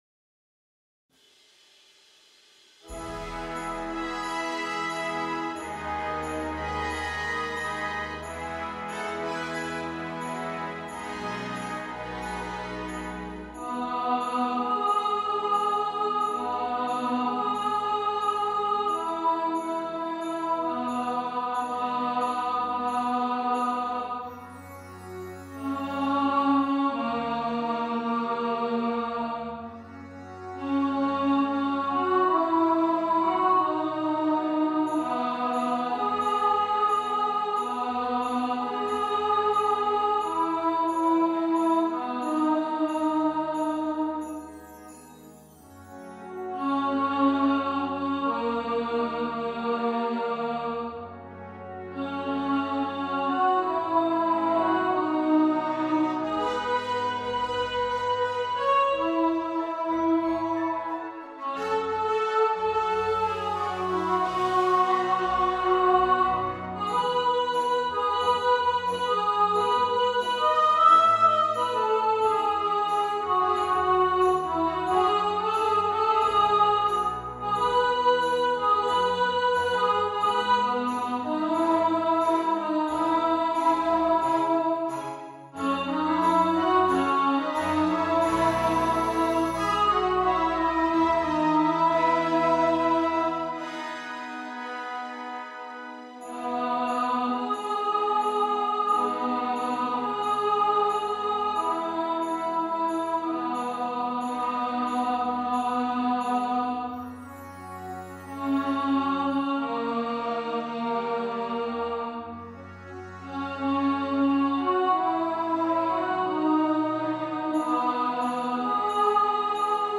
Believe (Polar Express) – Alto | Ipswich Hospital Community Choir